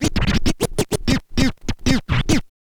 scratch_kit01_03.wav